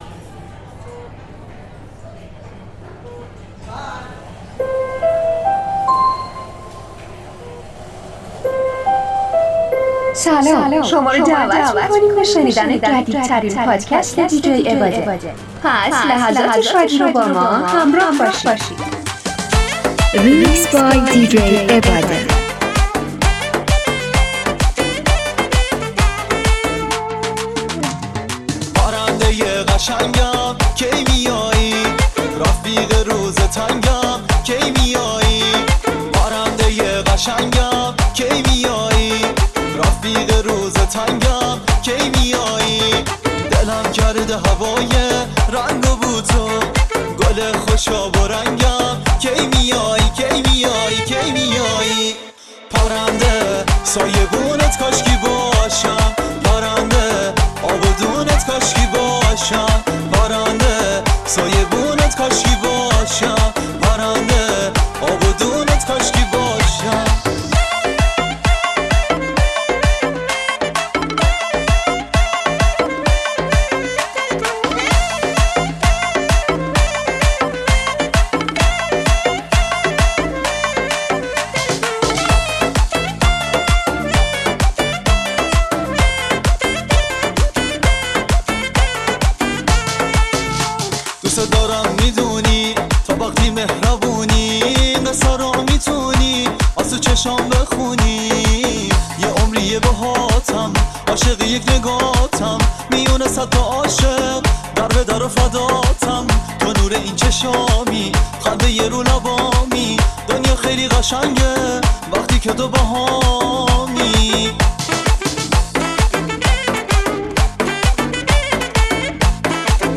میکس طولانی آهنگهای شاد ایرانی پرطرفدار